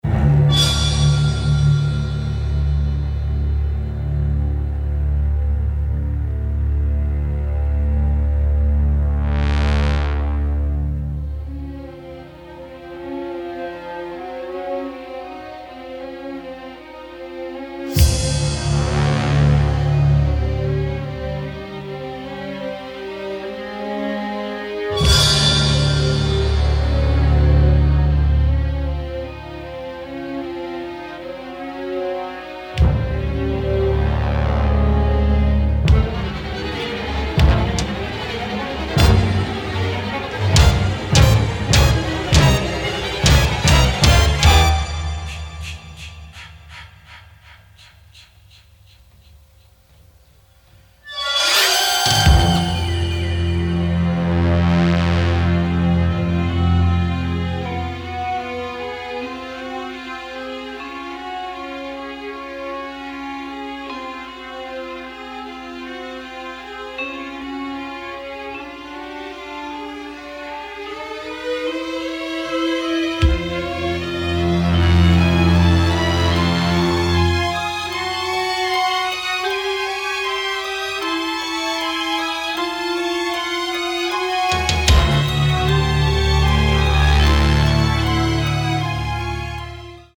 create an unique blend of orchestral and electronic music